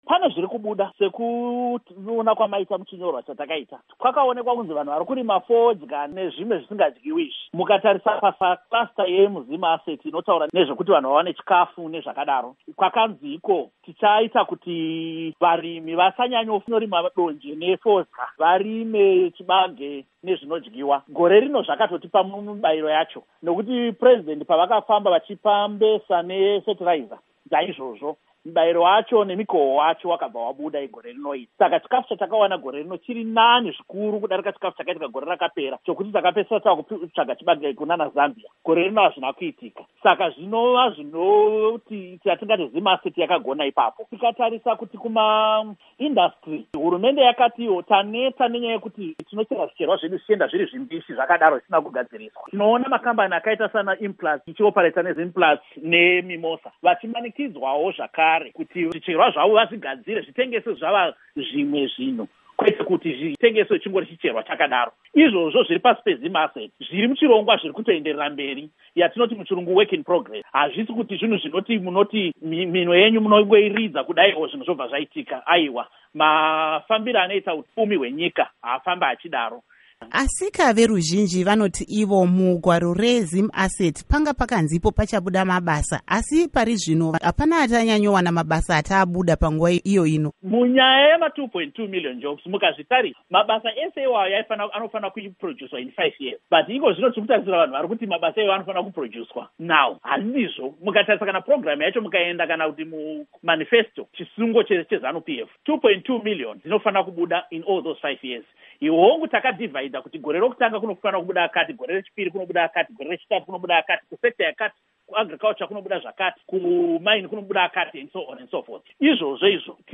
Interview With Nick Mangwana